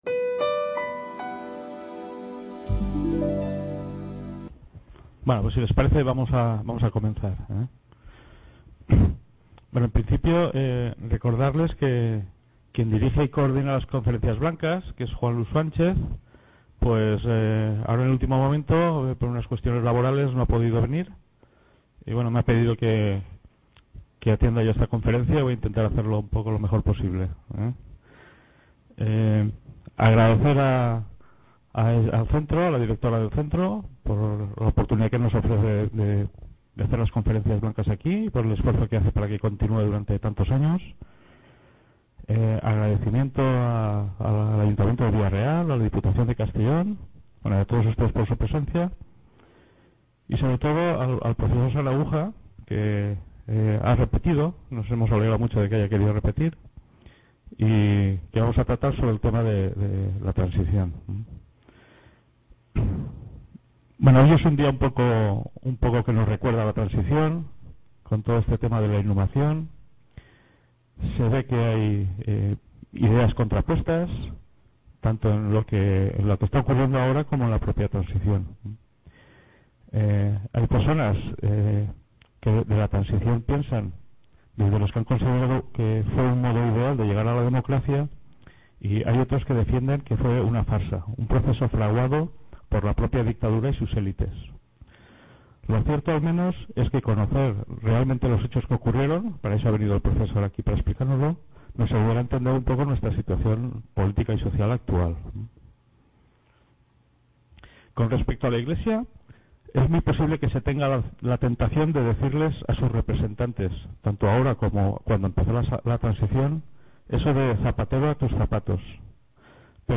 Conferencia Blanca: El papel de la Iglesia en la… | Repositorio Digital